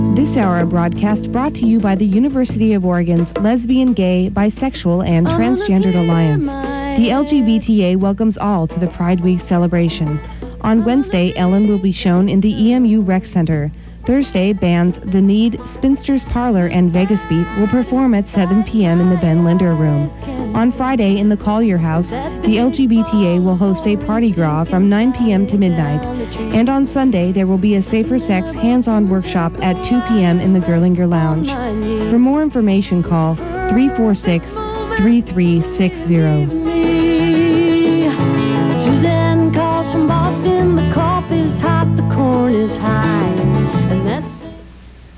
lifeblood: bootlegs: 1997-04-04: kwva - eugene, oregon
04. sponsor information (0:45)